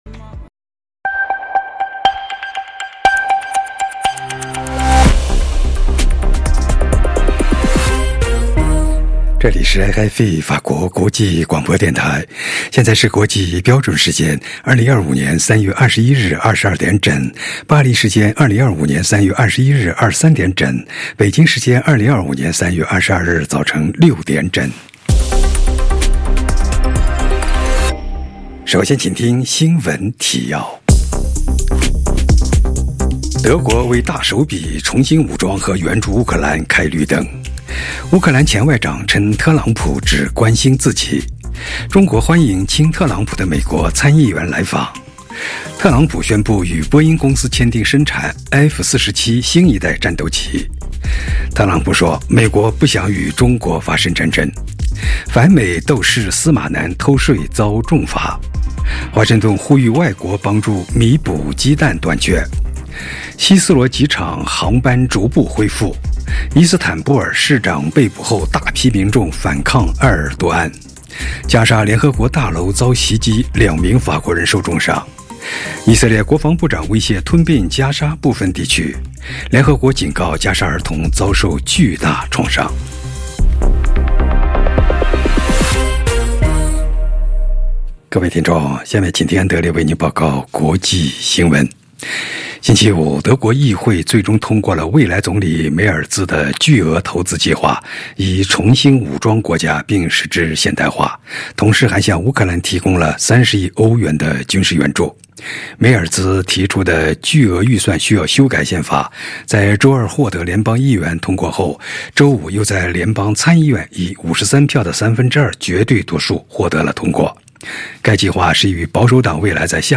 再次收听 - 新闻节目 21/03 22h00 GMT